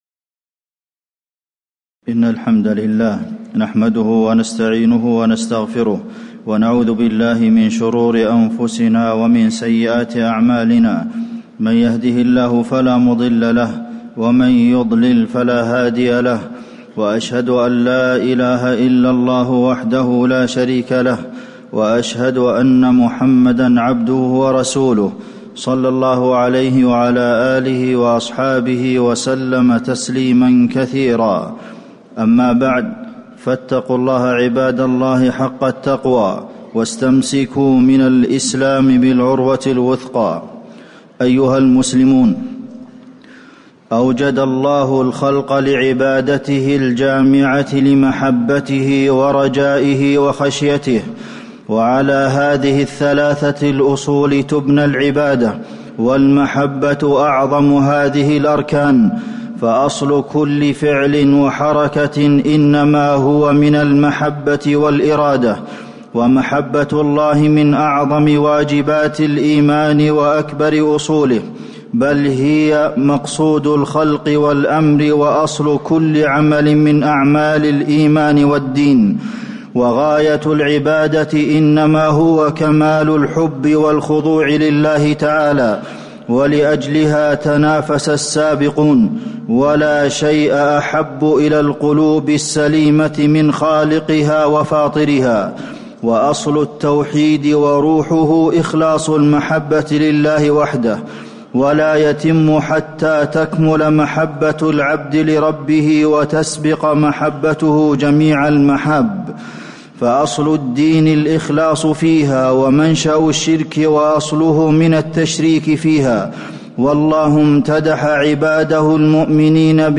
تاريخ النشر ٢٥ جمادى الآخرة ١٤٣٨ هـ المكان: المسجد النبوي الشيخ: فضيلة الشيخ د. عبدالمحسن بن محمد القاسم فضيلة الشيخ د. عبدالمحسن بن محمد القاسم آثار محبة الله عز وجل